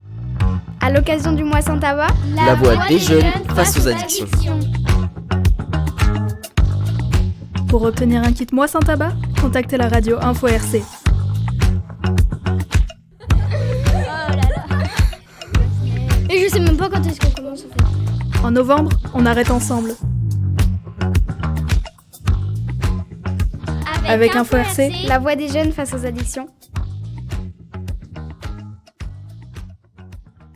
Pour le Mois sans tabac, des jeunes du sud Ardèche ont créé un spot radio dynamique pour informer les auditeurs sur la possibilité d’obtenir un kit pour arrêter de fumer.